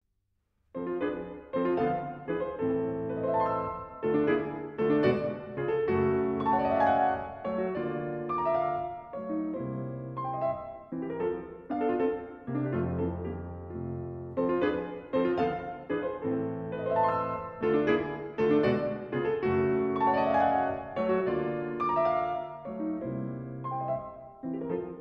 Bagatelles, Op. 147 - No. 2 In B Minor: Moderato sostenuto